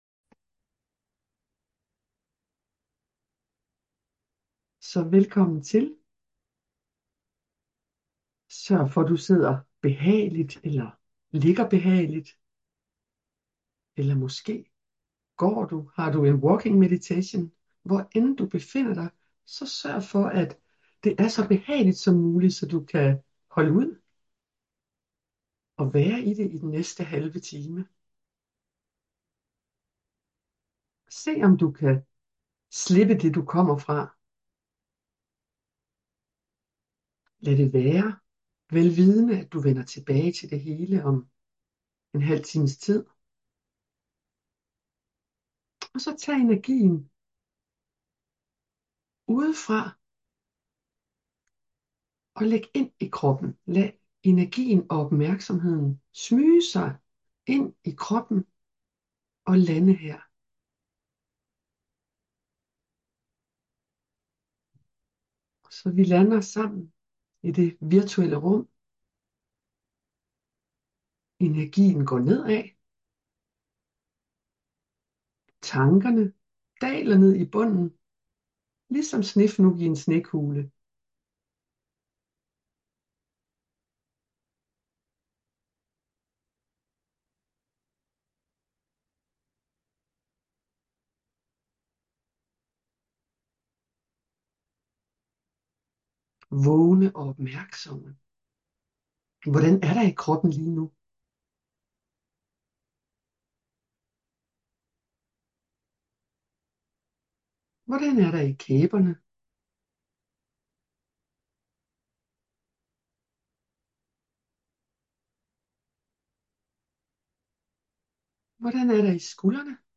Her kan du lytte til og downloade Guidede Morgen Meditationer af Mindfulness Foreningens medlemmer.
Morgen meditation – 26. august 2025